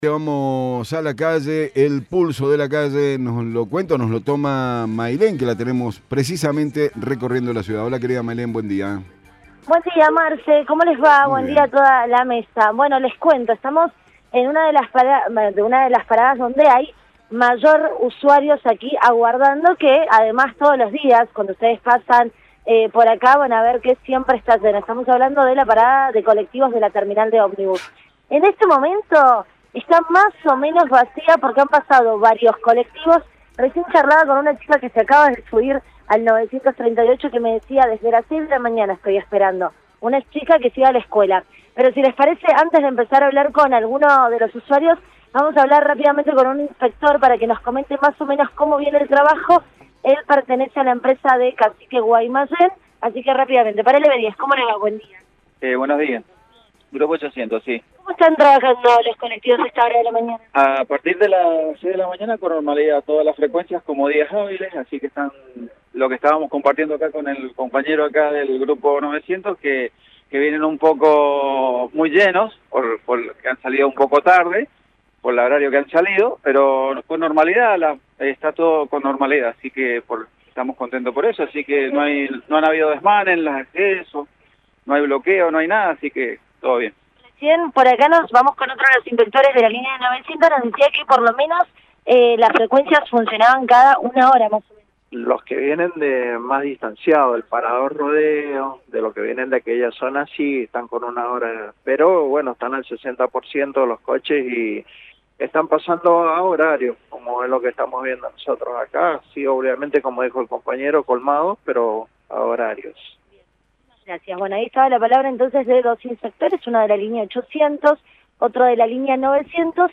Testimonios desde parada de colectivos de la Terminal de Omnibus